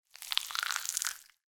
Коллекция включает аудиоэффекты для создания атмосферы хоррора: хруст костей, скрип скальпеля, всплески жидкостей.
Звук оторванного уха, прилипшего к поверхности, когда его отдирают